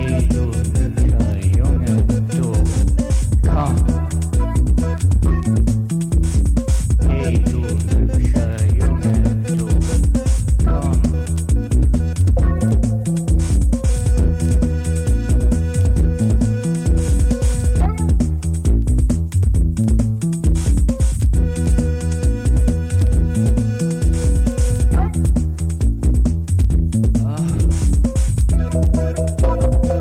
Chicago-meets-Vienna-vibes
808-driven beats
laidback jams
Electronix Wave Pop